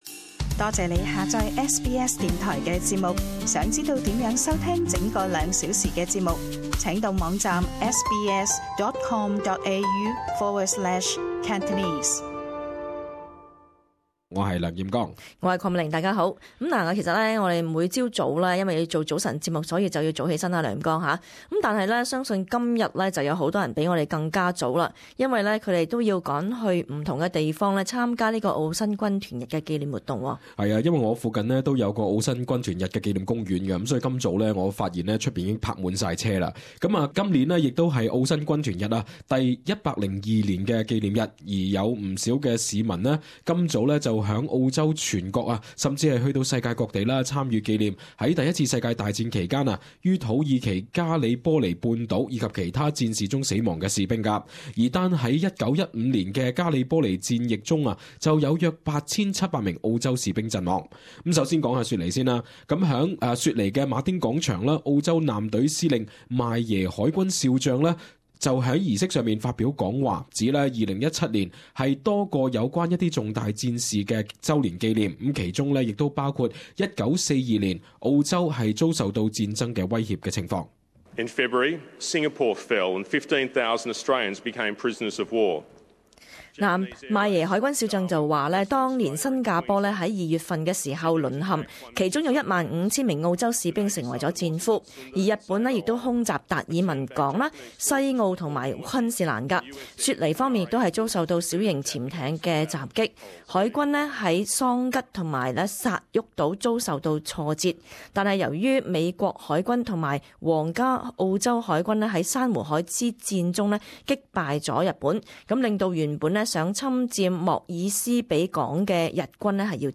【時事報導】澳洲及世界各地舉行澳新軍團日紀念儀式